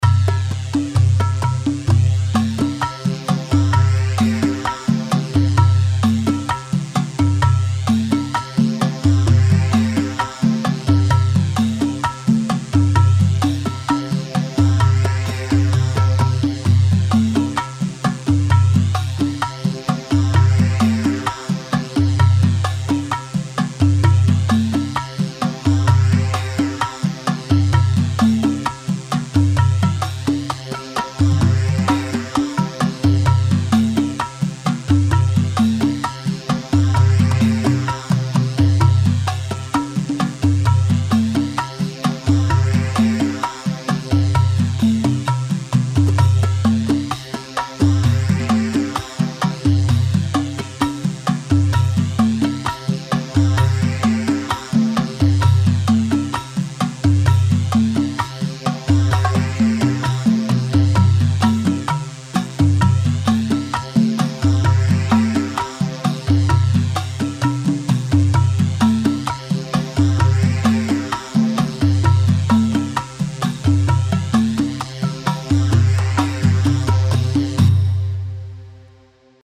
Rhuma 4/4 130 رومبا
Rhumba-130.mp3